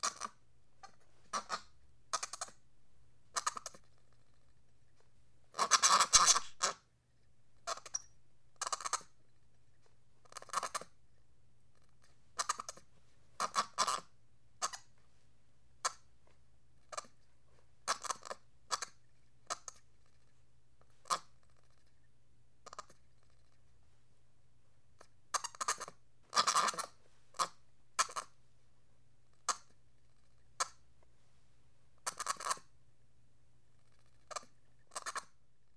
monkey-sound